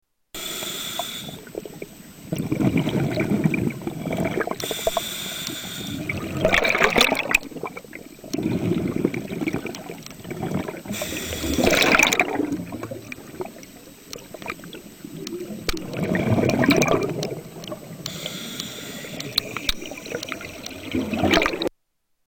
Scuba, underwater bubbles sound 1
Category: Animals/Nature   Right: Personal